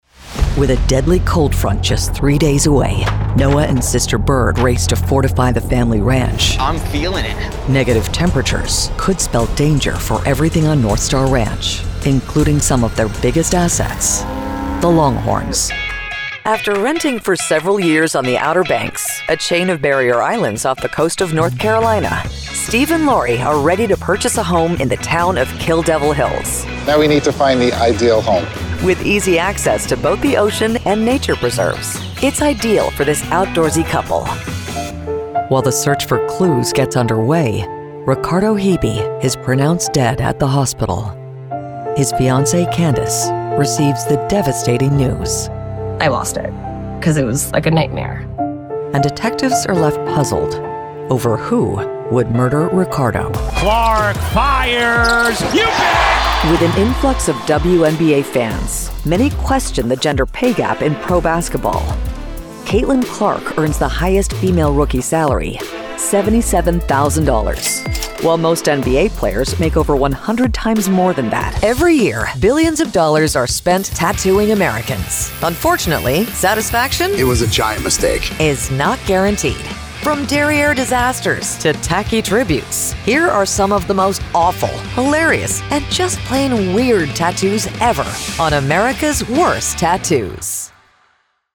Female Voice Over, Dan Wachs Talent Agency.
Expressive, Thoughtful, Versatile
TV Narration